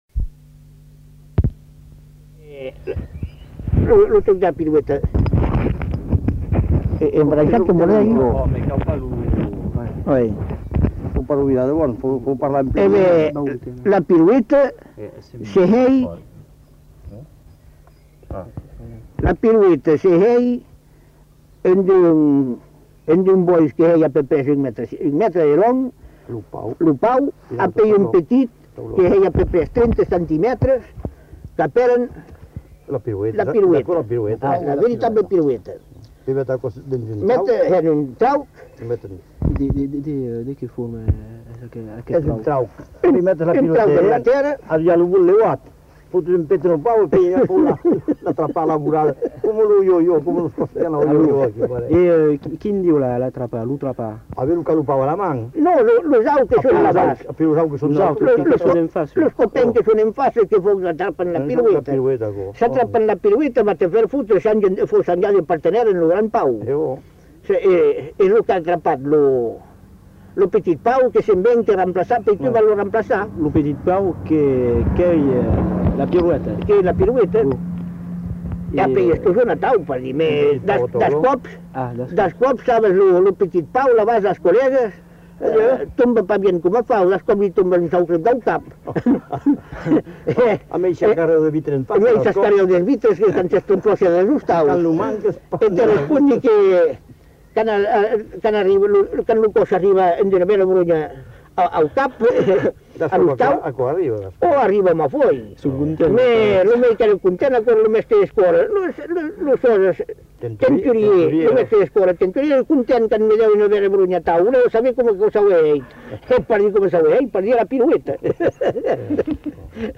enquêtes sonores